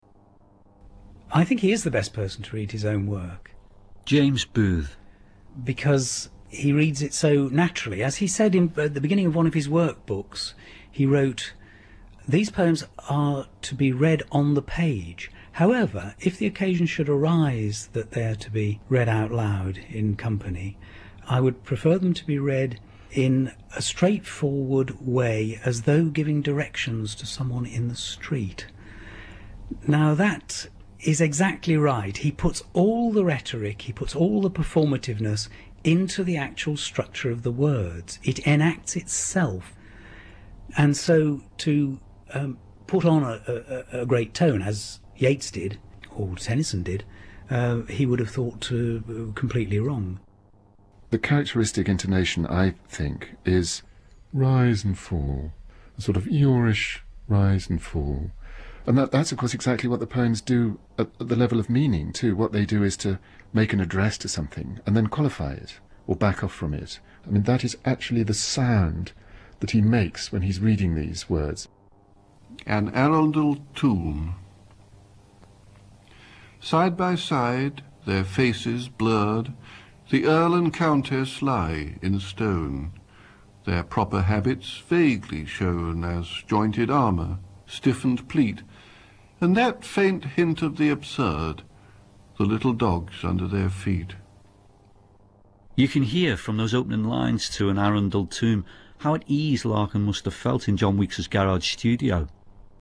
Vorig jaar doken er ineens onbekende geluidsopnamen op van de dichter Philip Larkin, met poëzie. Ze werden gemaakt in de tot studio omgebouwde garage van een bevriende geluidstechnicus, en waren bedoeld voor een lp, uit te geven in Amerika.
larkin_speechpatterns.mp3